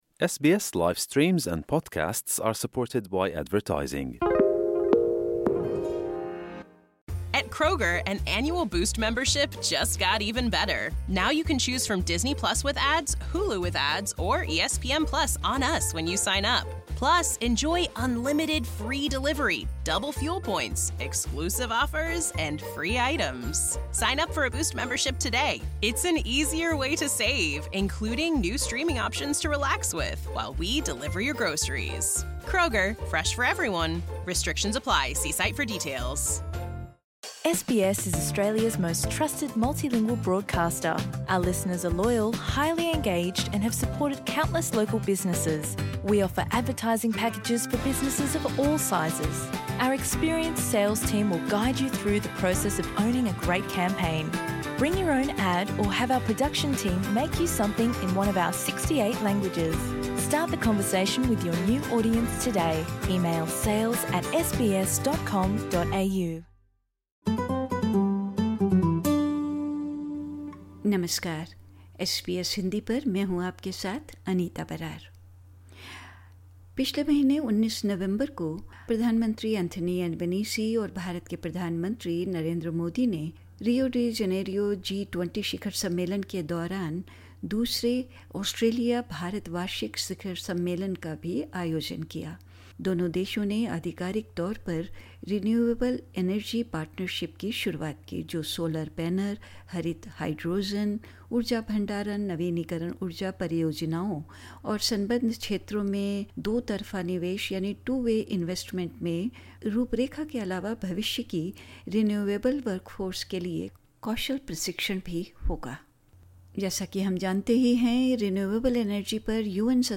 Australia and India officially launched the 'Renewable Energy Partnership (REP)' on 19 November 2024, during the 2nd Australia-India Annual Summit. In an interview with SBS Hindi, Federal Minister for Climate Change and Energy, Chris Bowen, highlighted the partnership's objectives and its potential to unlock new economic opportunities while advancing a clean energy future.